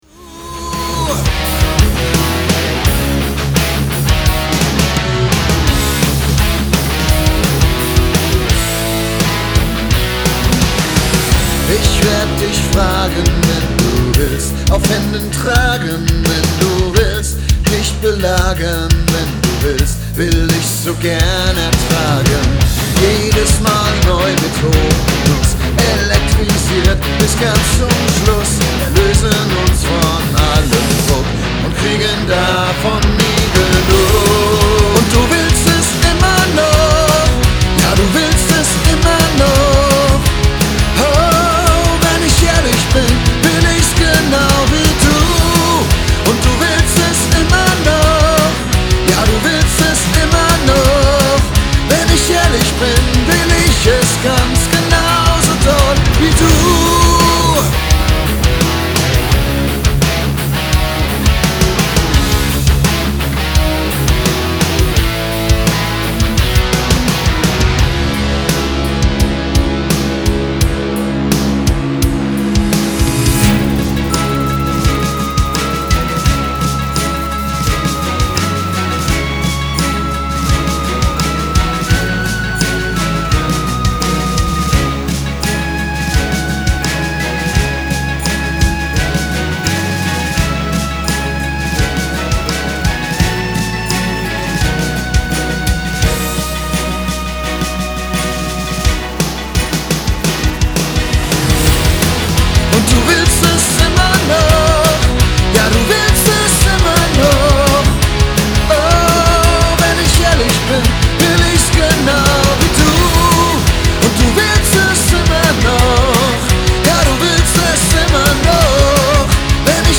Ich habe einfach mal das Preset Hard Rock - Transient Priority genommen und den 16Bit Dieter aktiviert, Settings s. Screenshot.